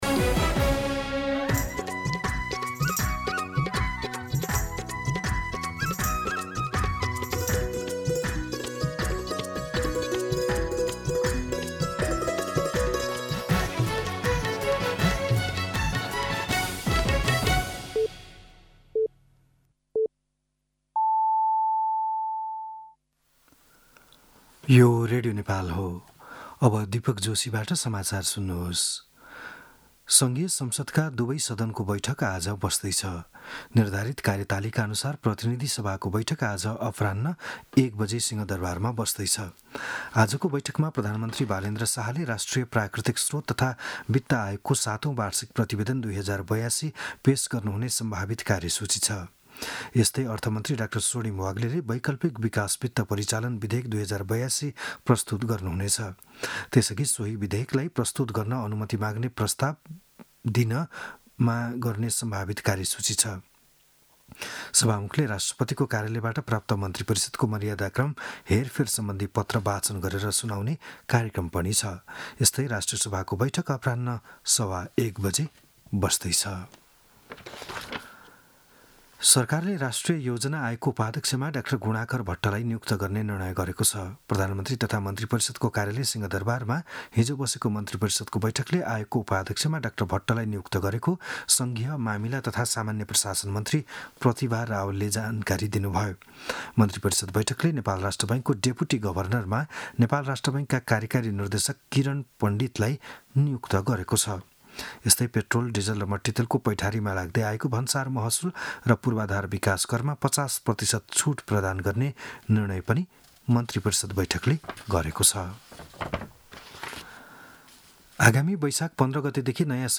बिहान ११ बजेको नेपाली समाचार : २५ चैत , २०८२